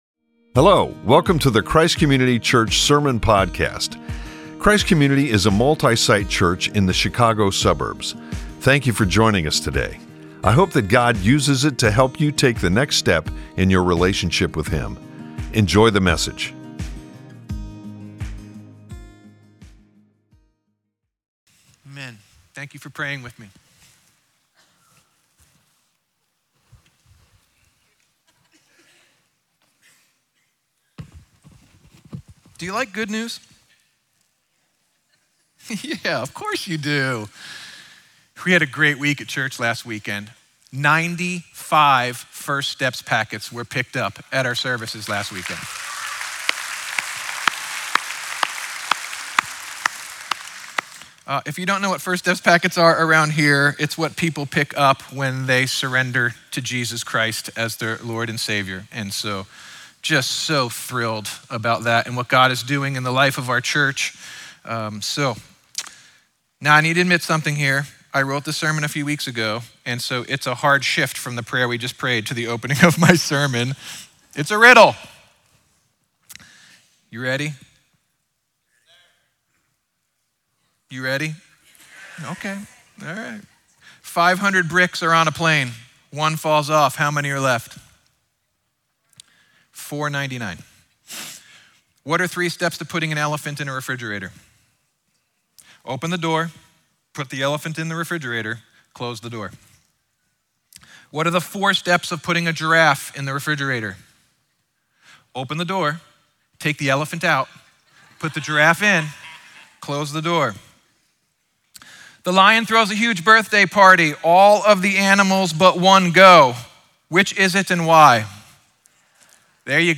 9-21-25-Sermon.mp3